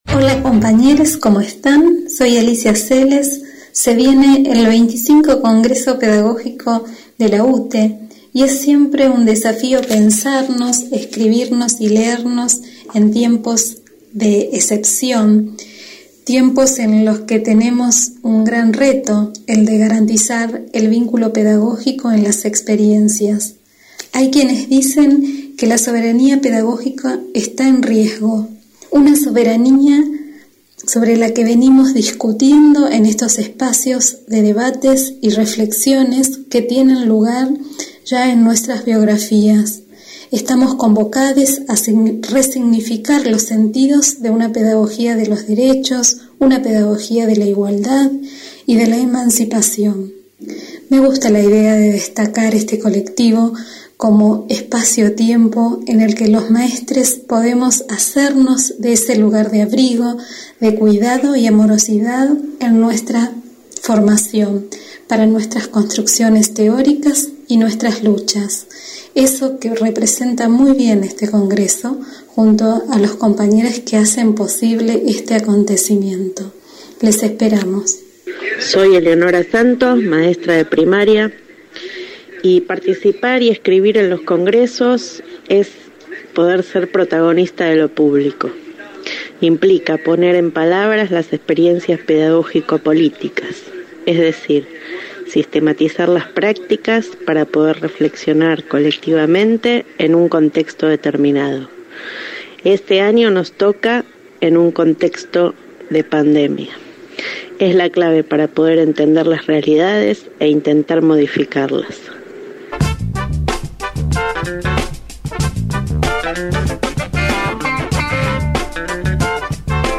Entrevista Congreso Pedagógico 01/06/20